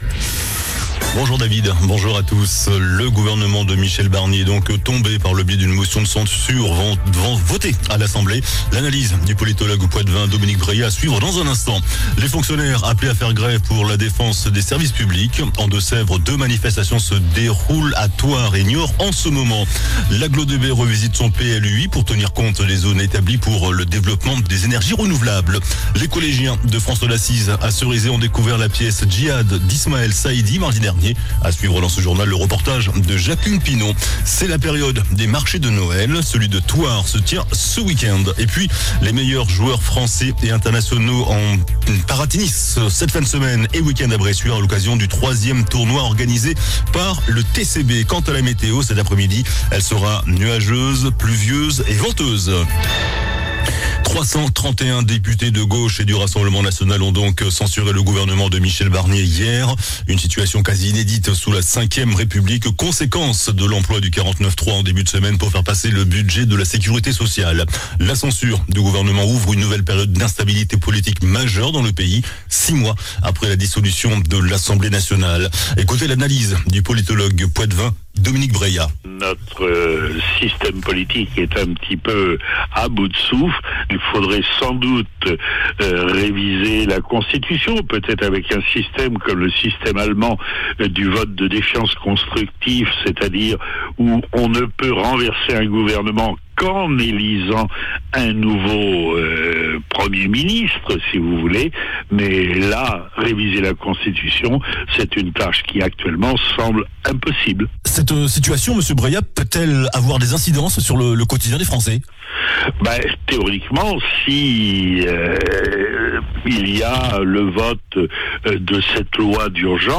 JOURNAL DU JEUDI 05 DECEMBRE ( MIDI )